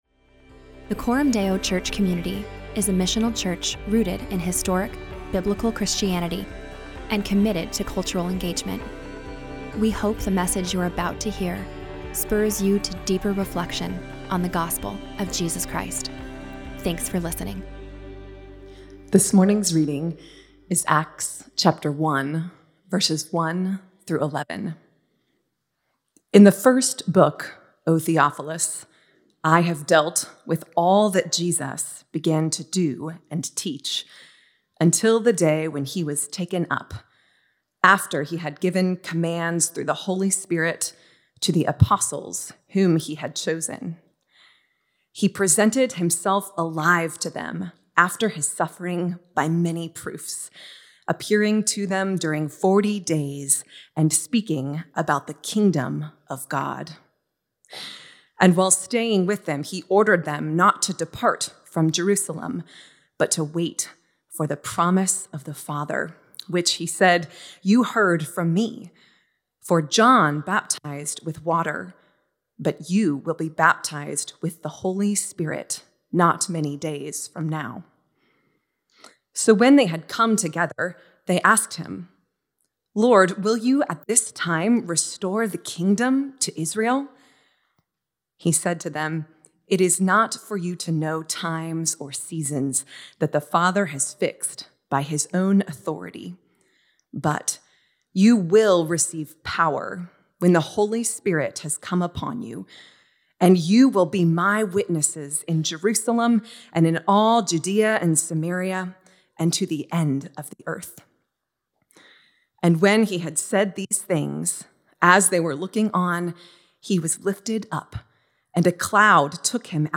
In this preaching series, we seek to help listeners understand the “big picture” of the Bible and submit their lives to King Jes